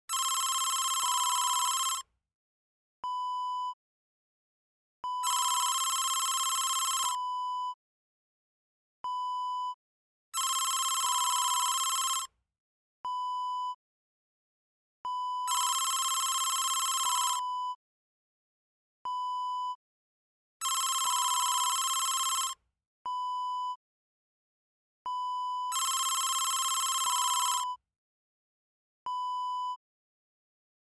Telephone Ringing Wav Sound Effect #2
Description: The sound of a phone ringing
Properties: 48.000 kHz 24-bit Stereo
A beep sound is embedded in the audio preview file but it is not present in the high resolution downloadable wav file.
Keywords: telephone, phone, ring, ringing, home, house, office
telephone-ringing-preview-3.mp3